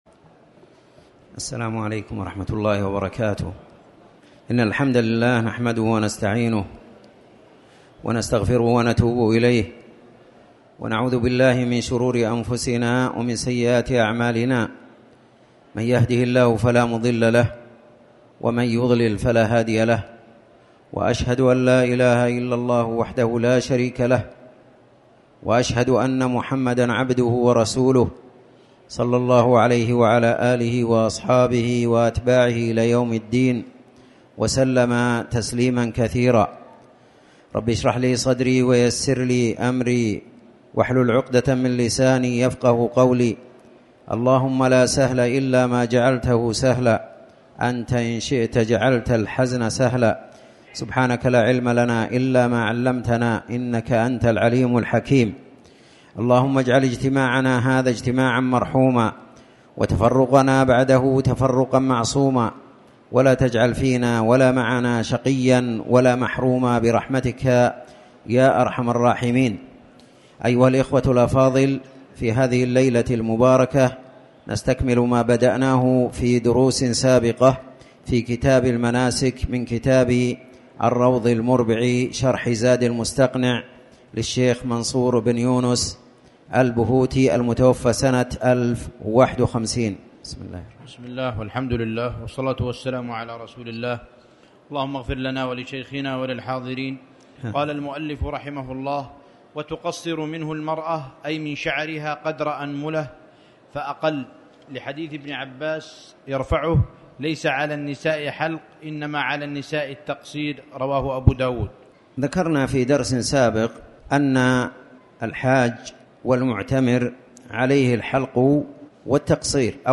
تاريخ النشر ١٧ صفر ١٤٣٩ هـ المكان: المسجد الحرام الشيخ